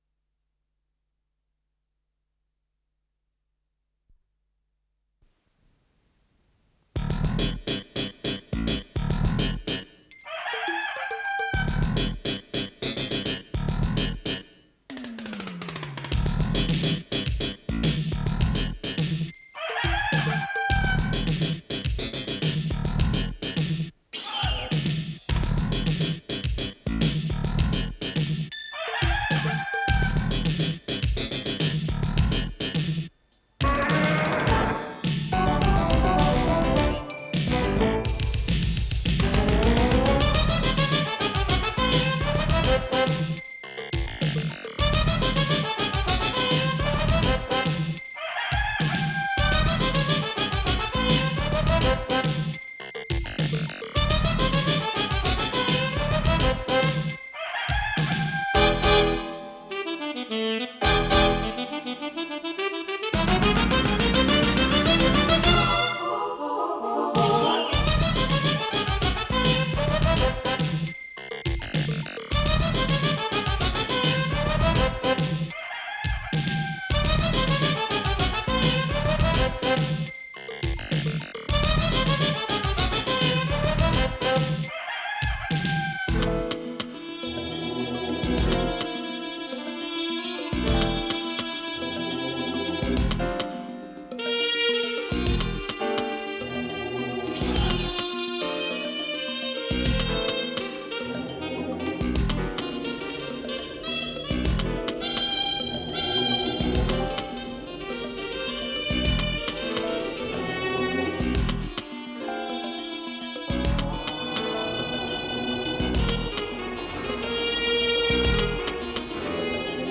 запись с наложением на синтезаторе
ВариантДубль моно